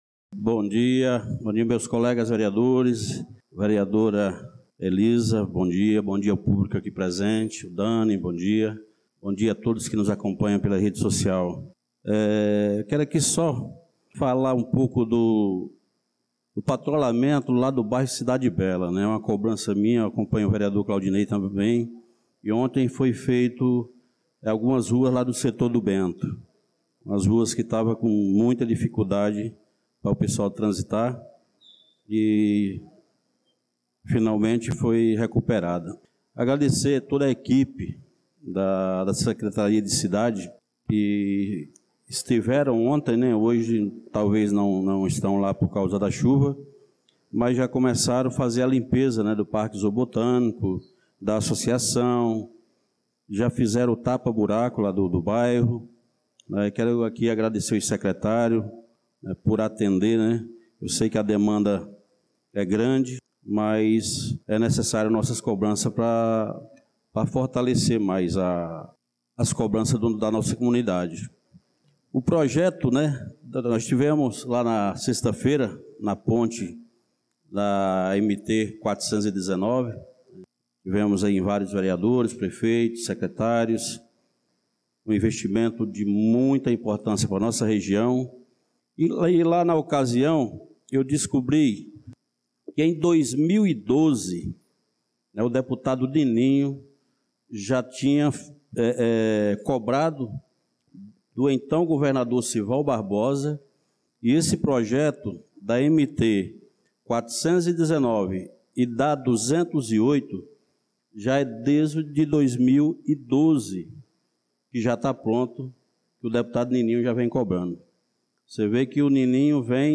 Pronunciamento do vereador Francisco Ailton na Sessão Ordinária do dia 25/02/2025